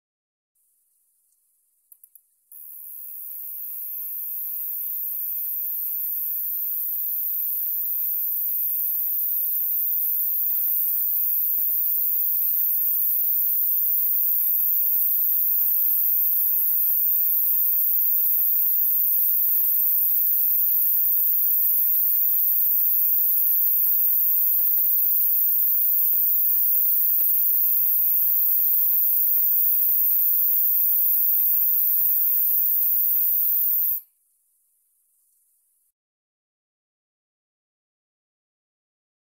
Enregistrement des cymbalisations du mâle Cicadetta montana.
c-montana.mp3